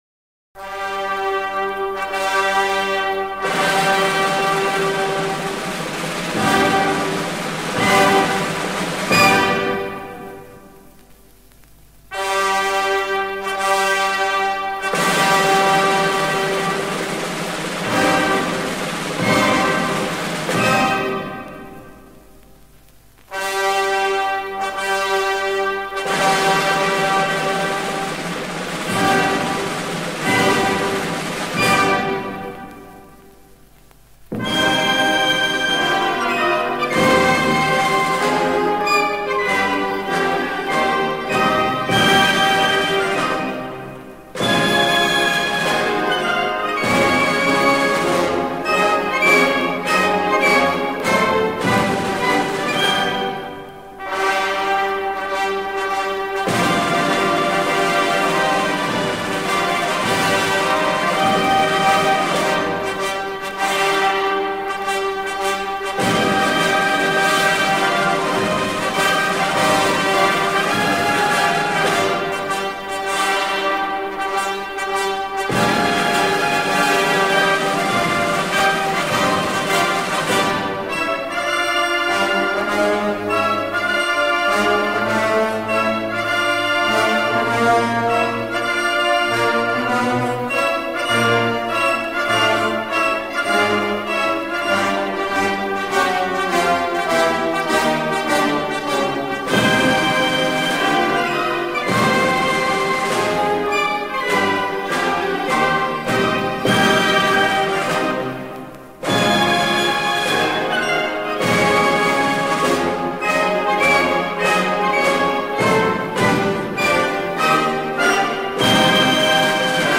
Marche-Funebre-pour-les-Funerailles-de-lEmpereur-Napoleon-1er.mp3